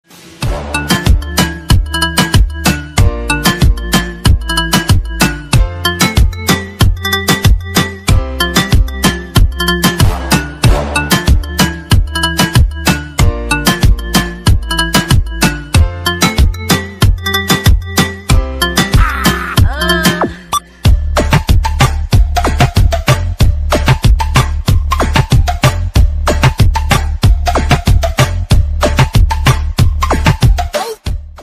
Обрезки dance песен